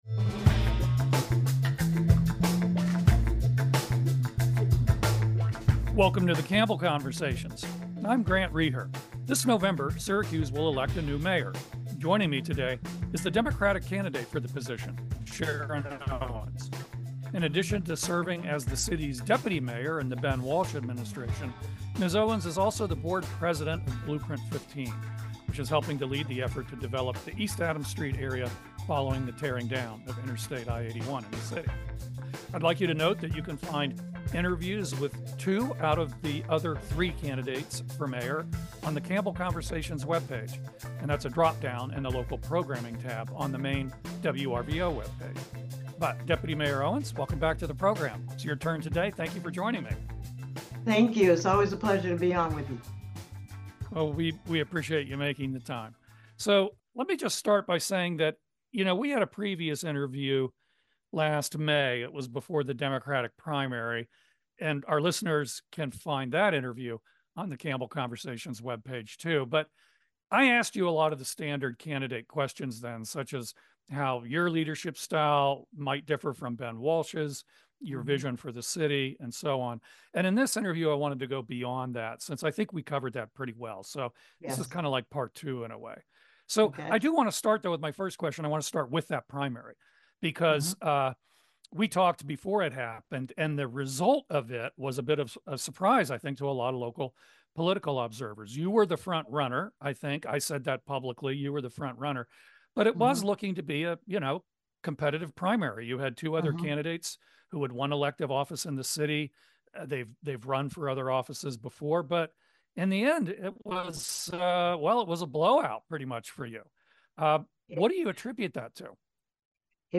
Guests include people from central New York -- writers, politicians, activists, public officials, and business professionals whose work affects the public life of the community -- as well as nationally prominent figures visiting the region to talk about their work.